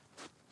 Sand Foot Step 2.wav